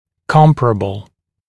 [‘kɔmpərəbl][‘компэрэбл]соизмеримый, сравнимый